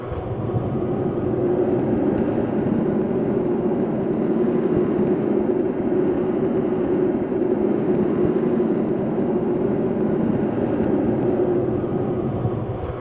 Nightmare ambiences Demo
RareNoise_1.wav